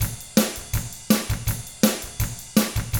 164ROCK T8-L.wav